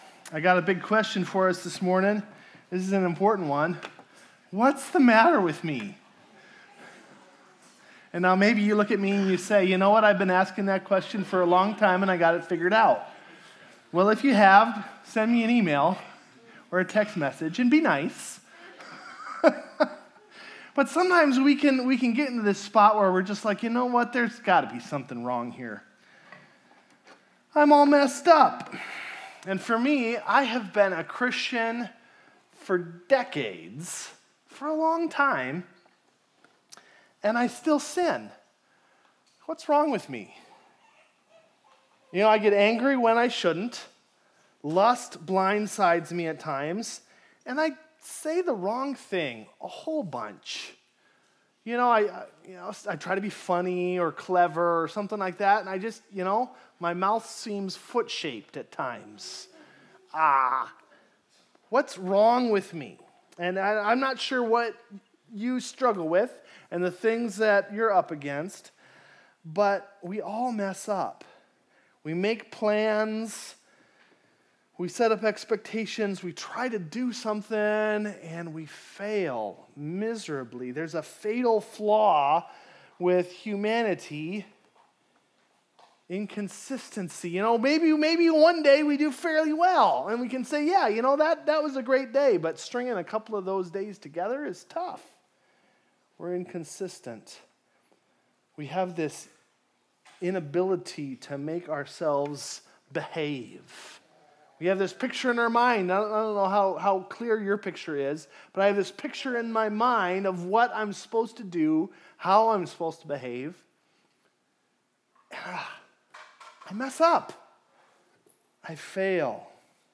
Video Audio Download Audio Home Resources Sermons What’s the matter with me?